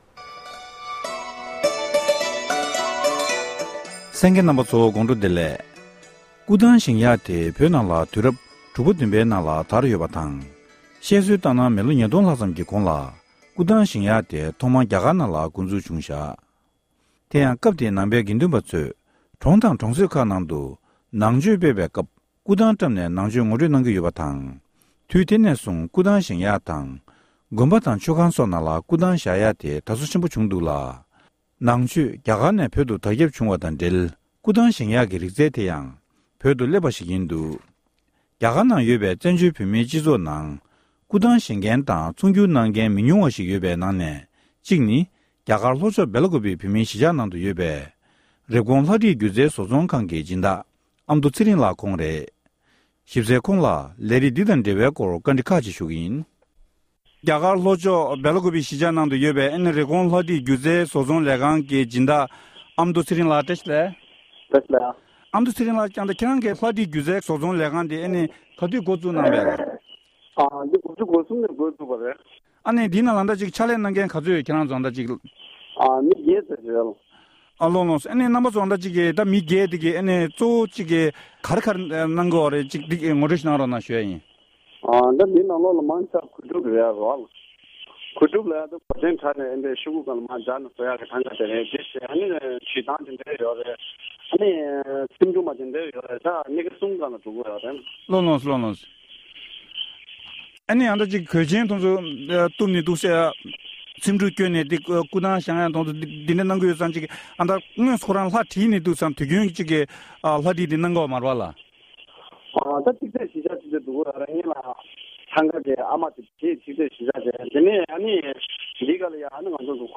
གནས་འདྲི་ཞུས་པ།